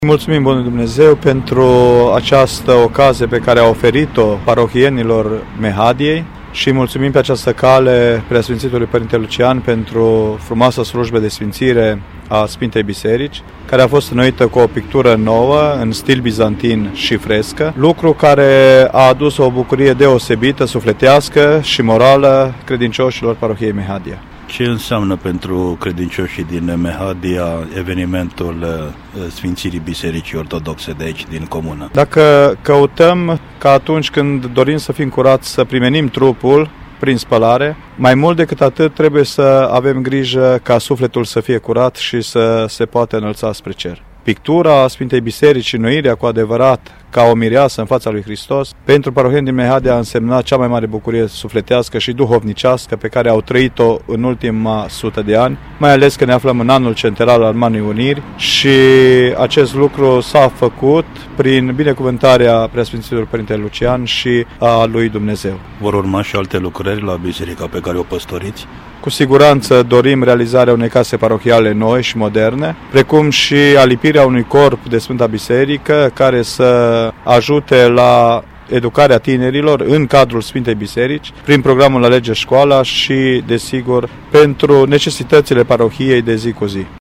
La sfârșitul manifestării, Prea Sfințitul Lucian – Episcopul Caransebeșului ne-a declarat :